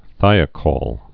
(thīə-kôl, -kōl, -kŏl)